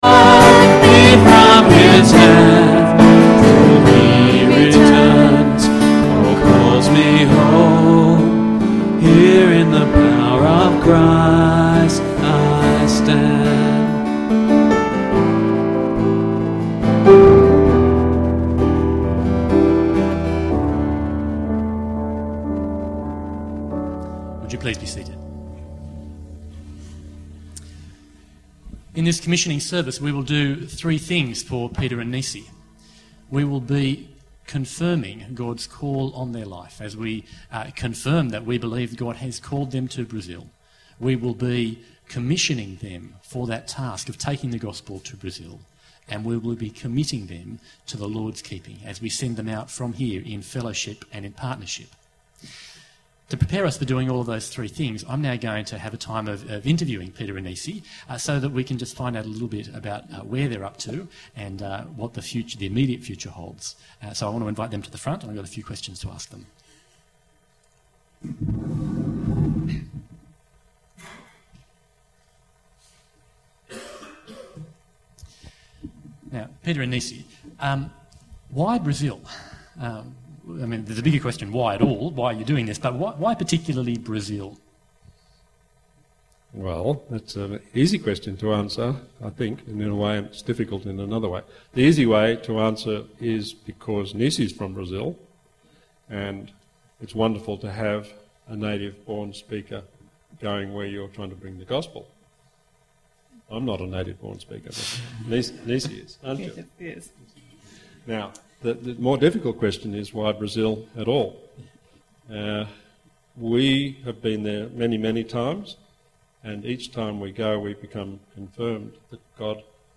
Commissioning Service (audio)